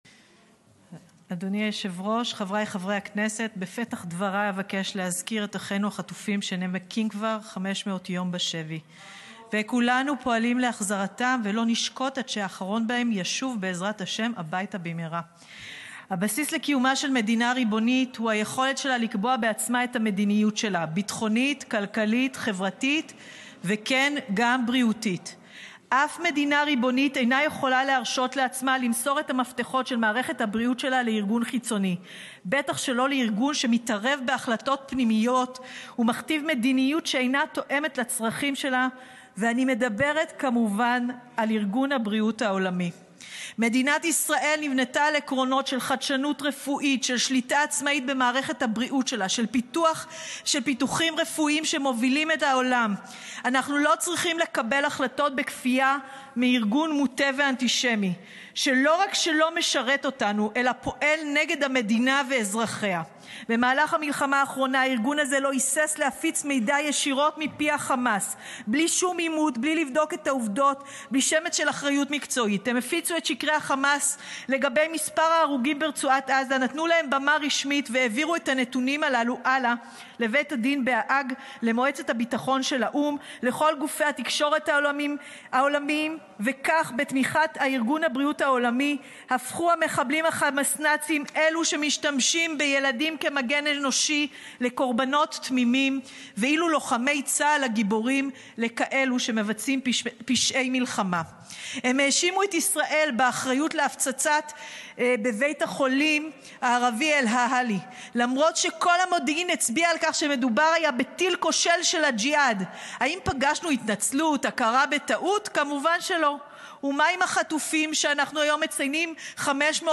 נאום היסטורי של חהכ לימור סון הר-מלך: ארהב הבינה את הסכנה מהארגון הזה ופרשה... ארגנטינה פרשה... עוד מדינות דנות בלפרוש מהארגון ורק בישראל ממתינים ומחכים שהמצב יהיה בלתי הפיך.חהכ...